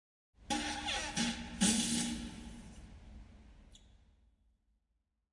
放屁9
描述：使用PlayStation Eye录制并使用Audacity编辑
标签： 肠胃胀气 直肠 flatulation 放屁 flatulate 胀气 底部 排便 放屁 直肠 poot 放屁
声道立体声